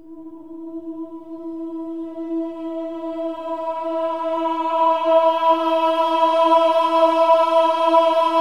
OH-AH  E4 -R.wav